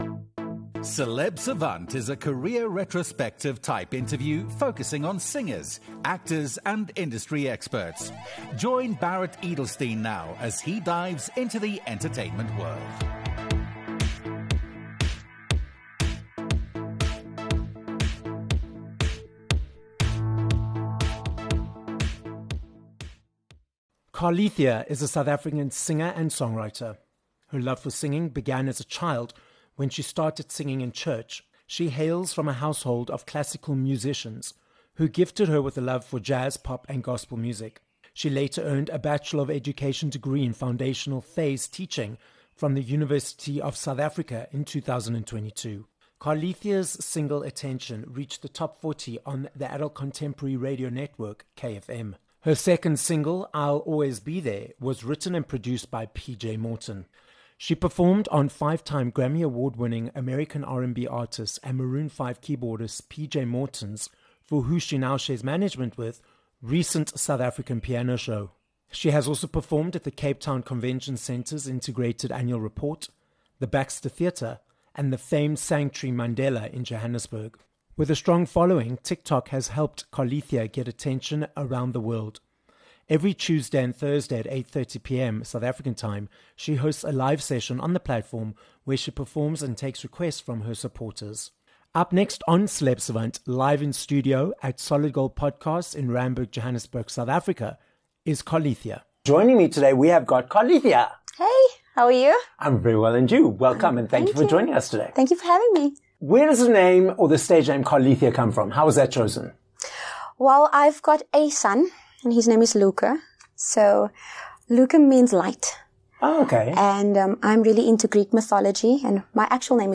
This episode was recorded live in studio at Solid Gold Podcasts, Johannesburg, South Africa.